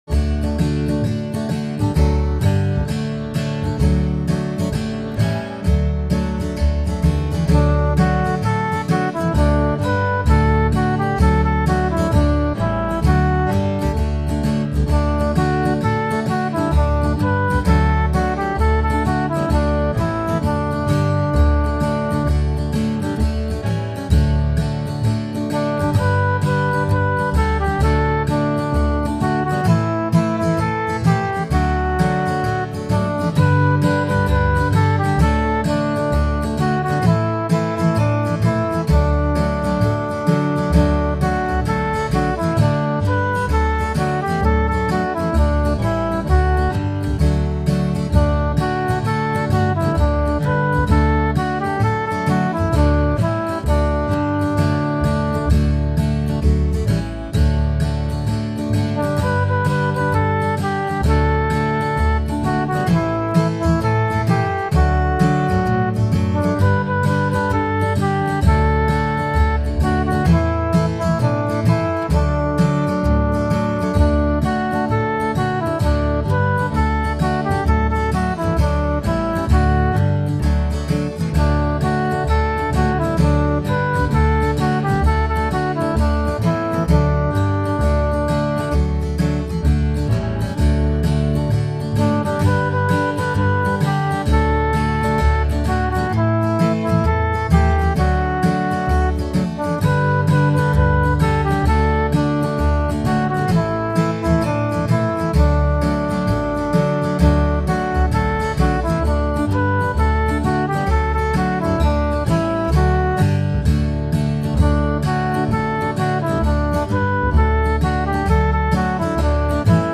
Another children’s song